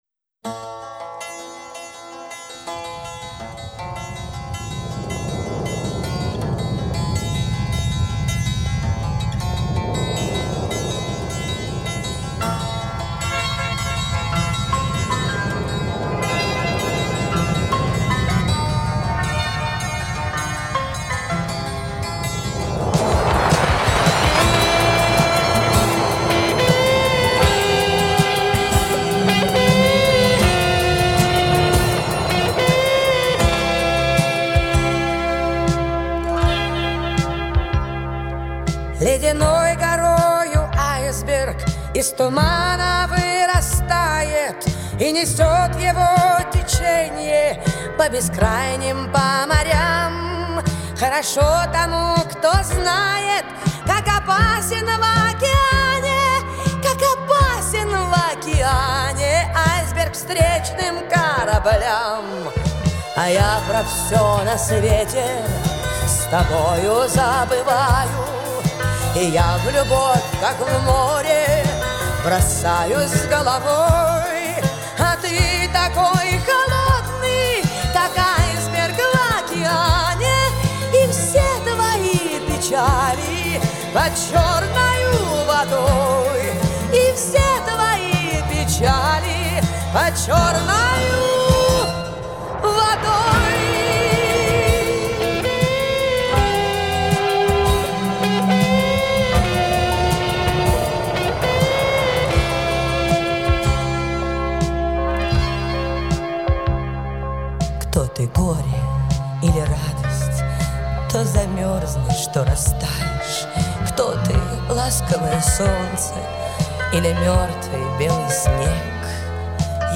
Популярная музыка